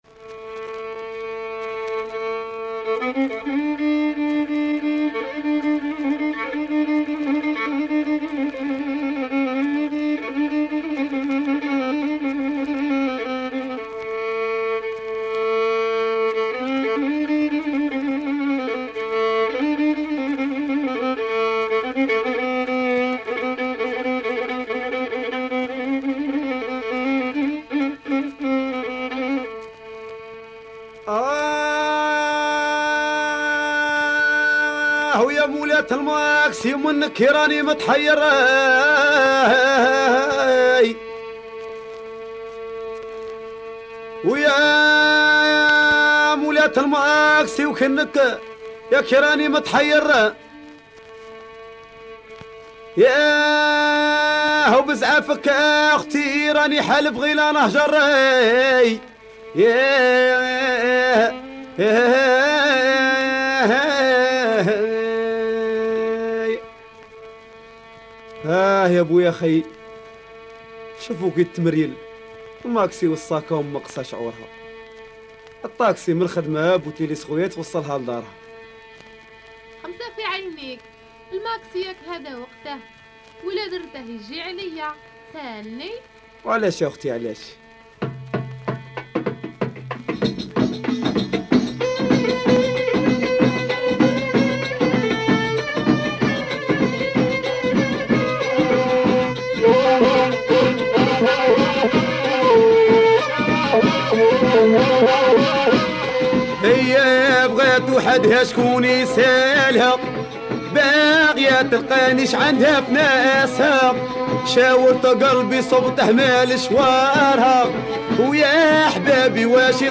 Super rare proto rai from Algeria